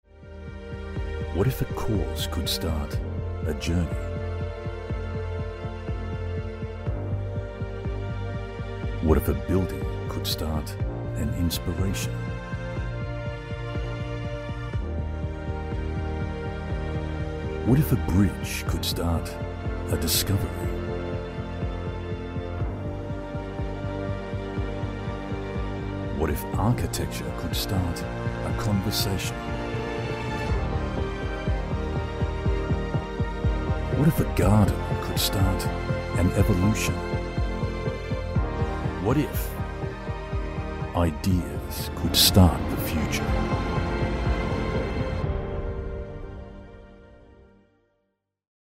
Inglês (australiano)
BarítonoGravesContraltoProfundoBaixo